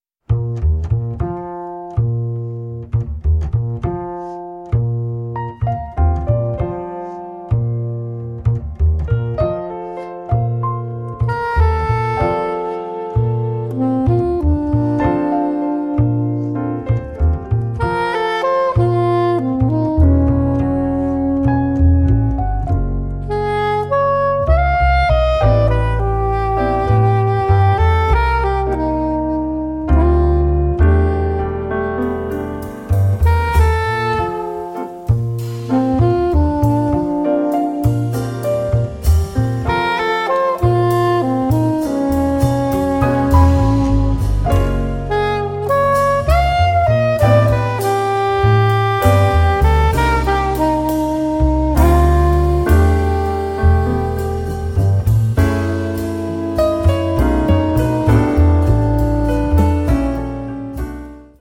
piano
sax tenore, soprano
contrabbasso
batteria
giocata sul sovrapporsi dei ritmi.